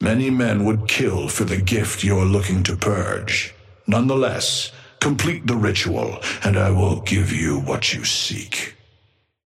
Amber Hand voice line - Many men would kill for the gift you're looking to purge.
Patron_male_ally_shiv_start_03.mp3